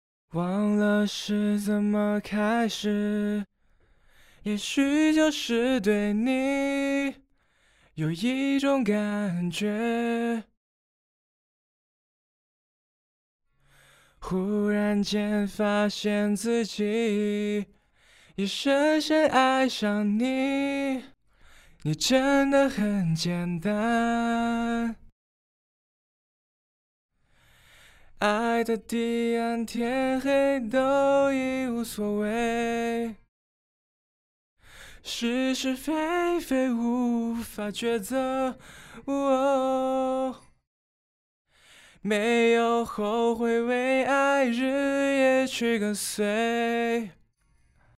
歌曲调式：升G大调 干声数量：1轨道